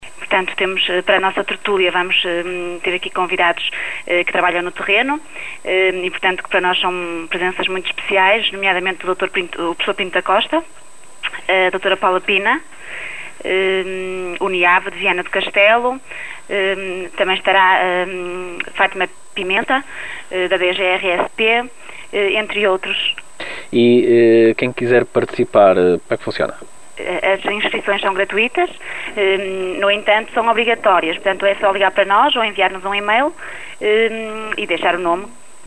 O médico e especialista em medicina legal é um dos convidados da iniciativa